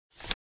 Unlock.wav